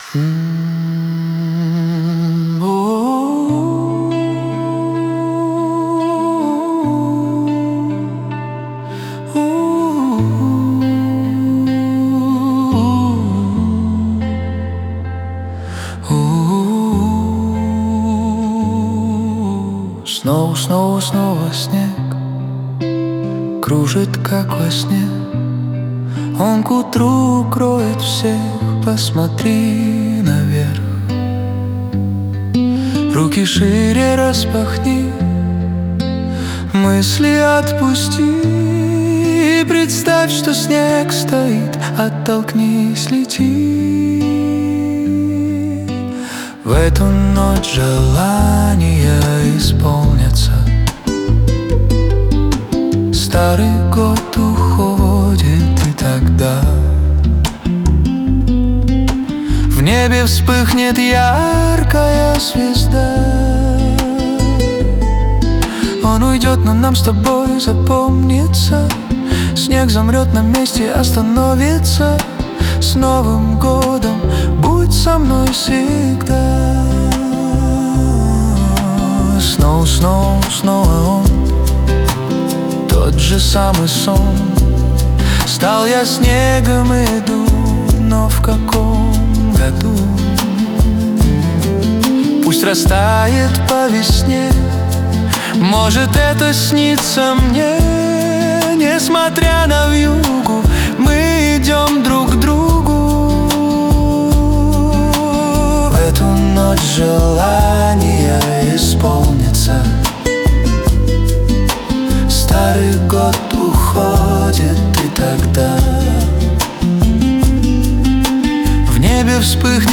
А эта новогодняя песня